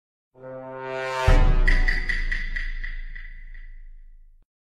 Among Us Sus - Bouton d'effet sonore